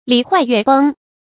禮壞樂崩 注音： ㄌㄧˇ ㄏㄨㄞˋ ㄩㄝˋ ㄅㄥ 讀音讀法： 意思解釋： 古代制禮，把它當作社會道德、行為的規范；把制樂人微言輕教化的規范。